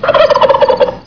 Categoria Effetti Sonori